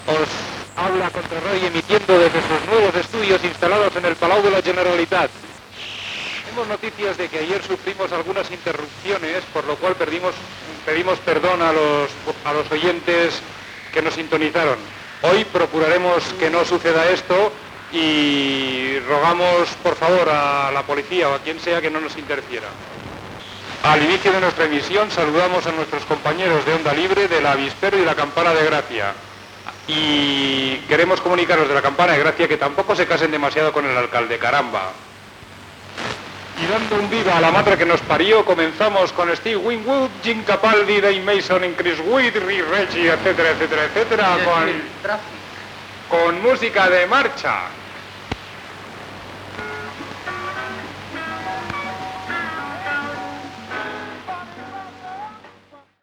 21a73682aa60079f717782c19b53a48be14172f0.mp3 Títol Contrarradio Emissora Contrarradio Titularitat Tercer sector Tercer sector Lliure Descripció Identificació, salutació a la resta de ràdios lliures i tema musical.